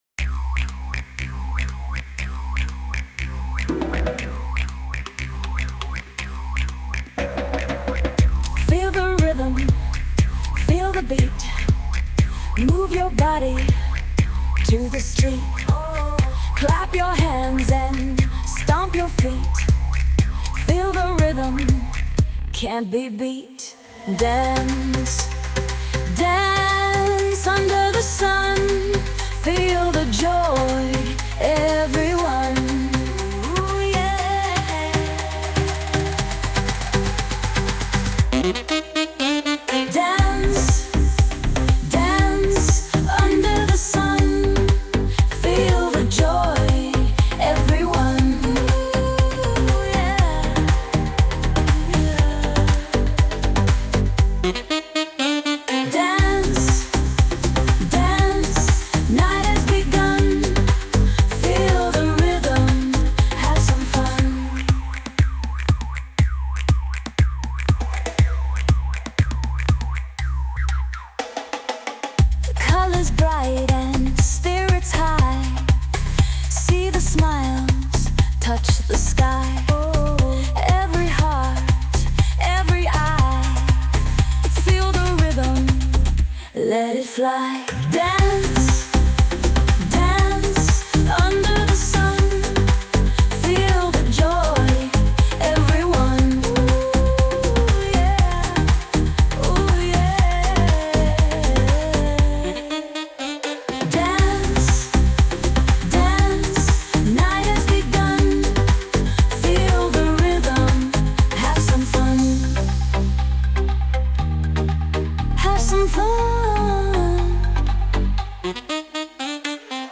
アップビートでノリの良いリズムに乗せて、女性ボーカルがクールに、そして少し色っぽく歌い上げるナンバー。
ただ明るく元気なだけではなく、大人の余裕やセクシーさをスパイスとして効かせた「かっこいい」一曲です。